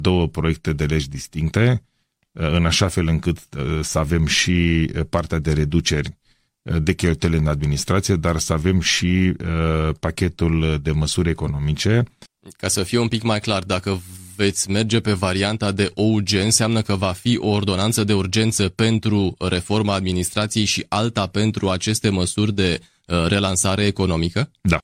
Insert audio Ilie Bolojan